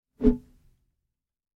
Быстро провели пальцами по укулеле